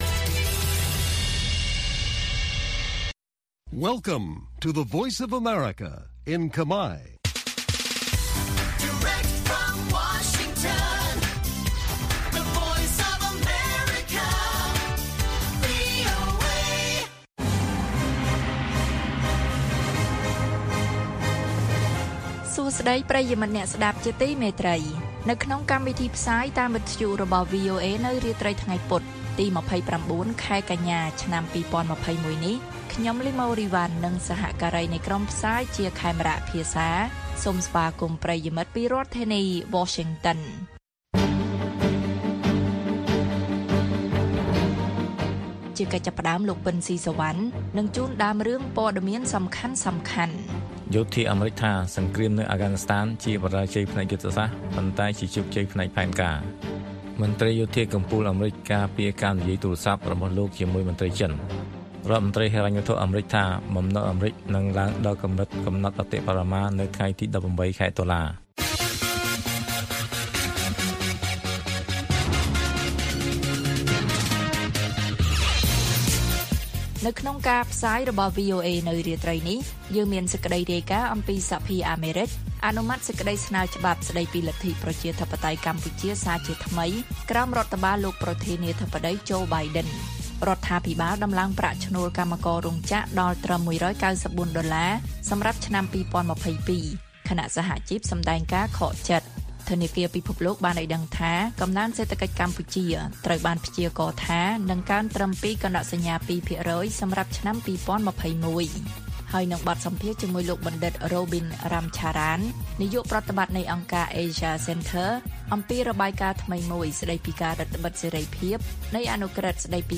បទសម្ភាសន៍ VOA៖ អ្នកជំនាញថា ច្រកទ្វារអ៊ីនធឺណិតកម្ពុជា នាំមកនូវការភ័យខ្លាចកាន់តែខ្លាំង។